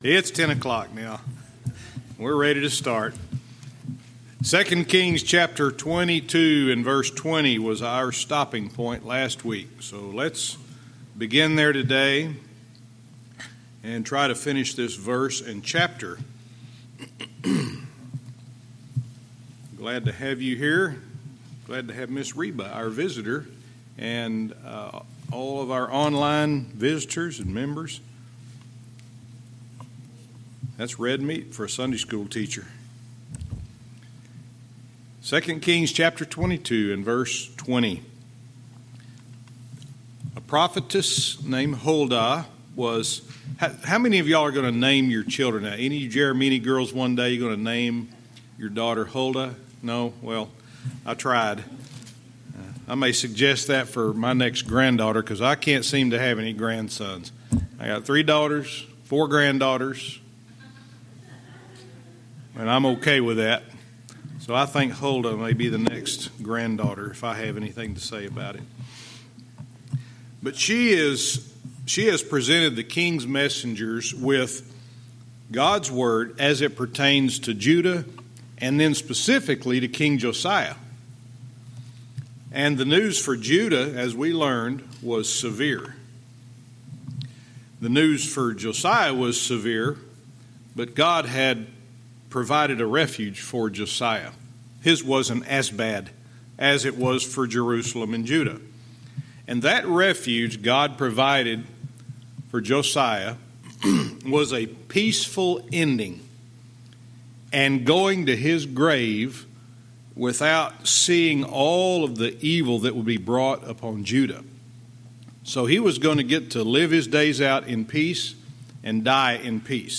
Verse by verse teaching - 2 Kings 22:20-23:2